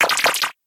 Catégorie:Cri Pokémon (Soleil et Lune) Catégorie:Cri d'Araqua